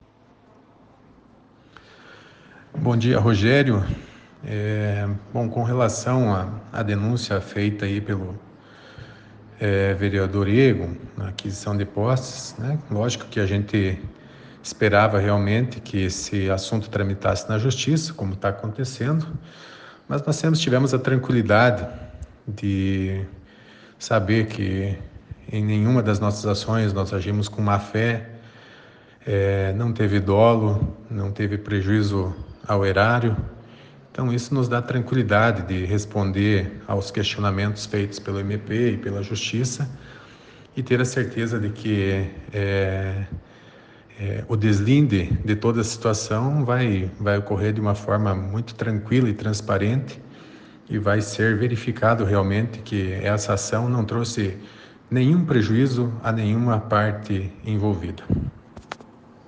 Ex-prefeito fala sobre a denúncia
E Edir Havrechaki falou sobre o assunto na edição desta quinta-feira, dia 13, do ‘Jornal da Cruzeiro’ (ouça nos áudios abaixo).